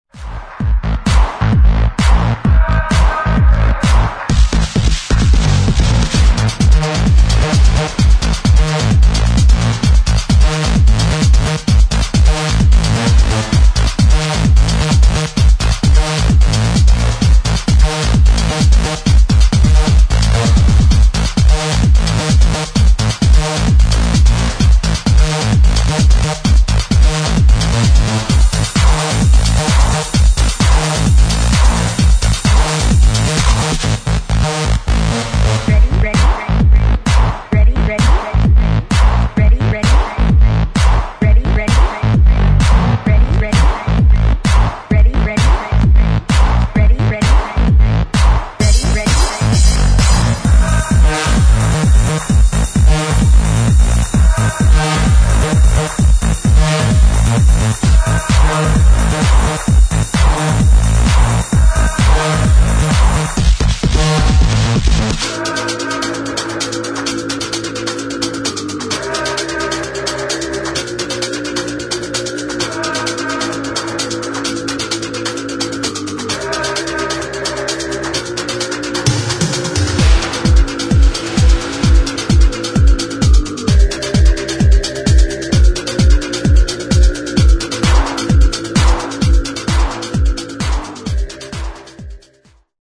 TECHNO / HOUSE